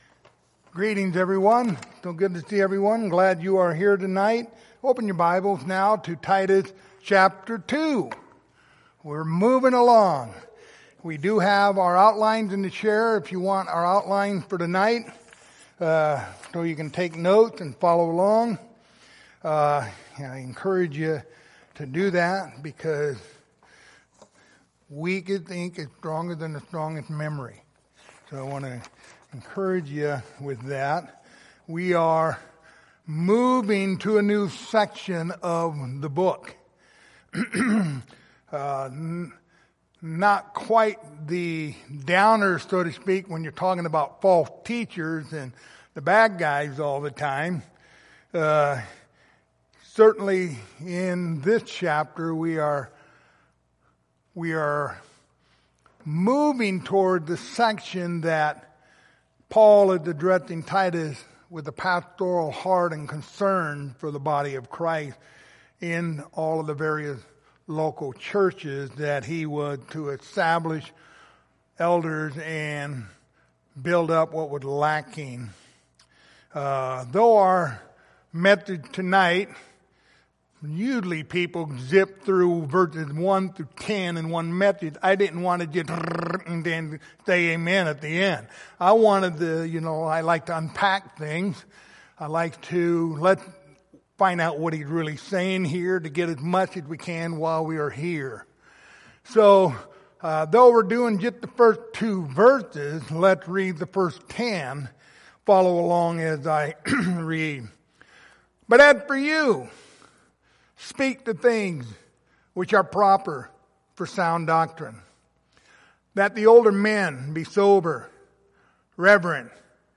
Passage: Titus 2:1-2 Service Type: Sunday Evening